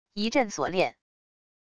一阵锁链wav音频